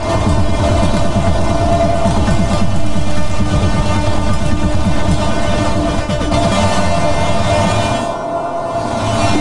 垫子" 速度
描述：在Native Instruments Reaktor和Adobe Audition中结合各种处理过的样本而创造的持续的环境垫音；适合在键盘的一个八度上传播
标签： 处理 环境 电子 工业 2-酒吧 声音设计
声道立体声